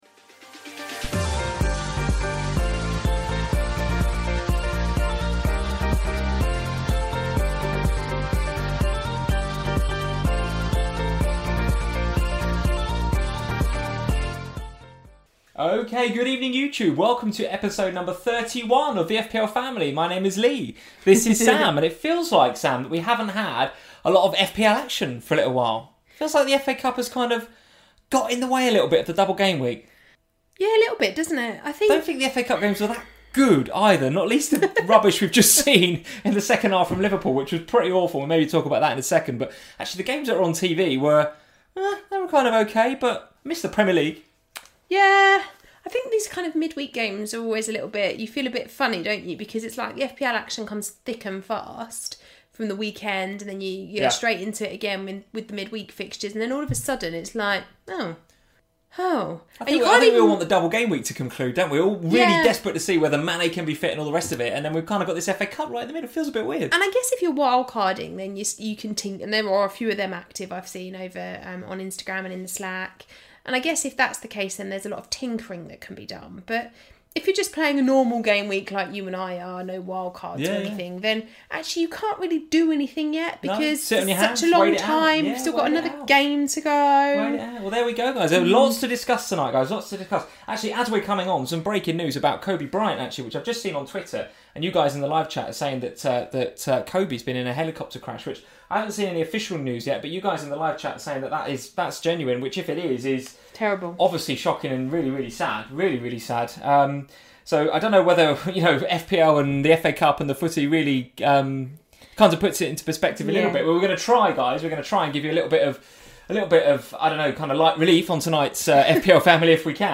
Welcome to FPL Family, a chat show dedicated to all things Fantasy Premier League.